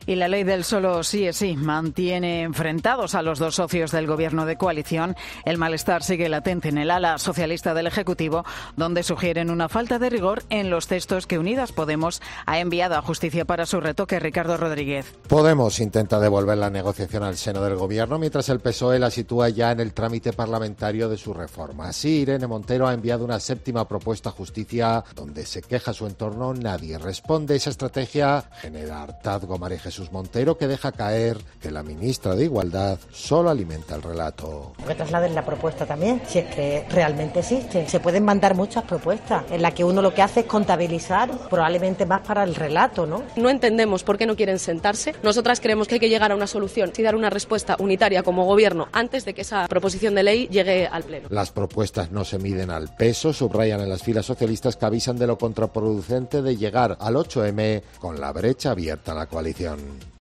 Enfrentamiento de declaraciones por la ley del 'sí es sí' entre las ministras de Hacienda e Igualdad
La ministra de Hacienda y vicesecretaria general del PSOE, María Jesús Montero, ha dejado caer en los pasillos del Congreso de los Diputados que la titular de Hacienda, con tantas propuestas, solo hace alimentar el relato.